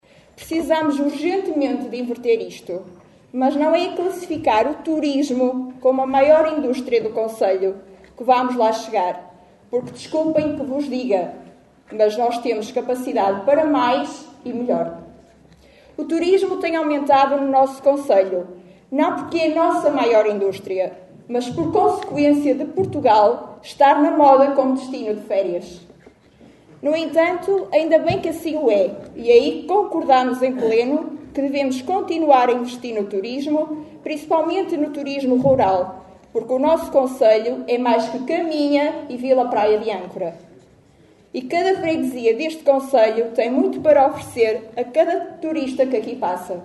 Extratos da última Assembleia Municipal de Caminha.